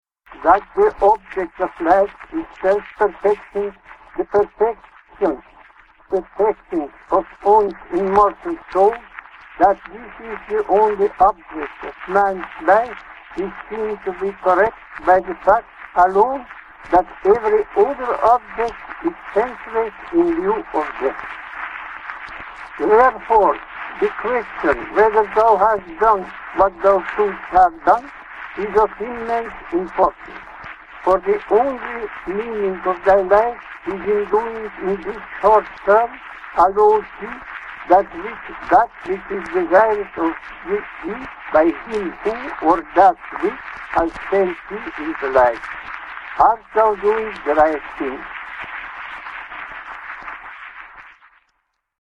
Leo Tolstoy - Original voice